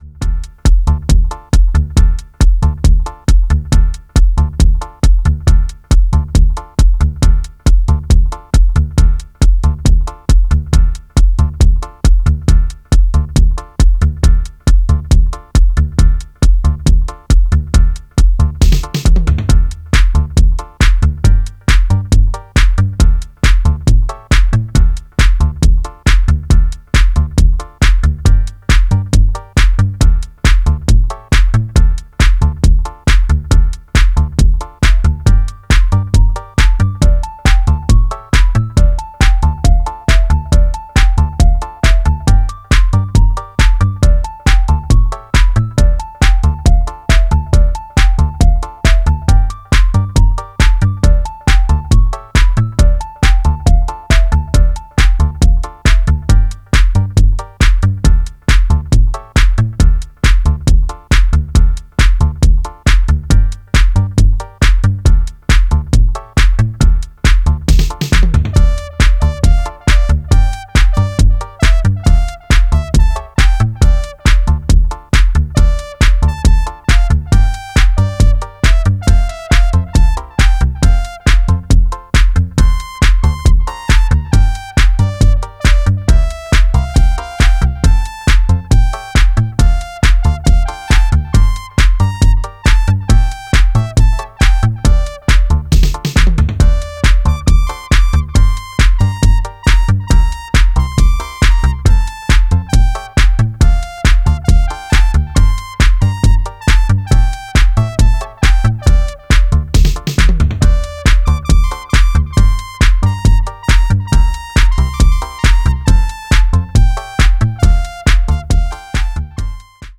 Disco House Techno